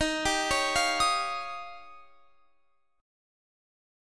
Listen to Eb9 strummed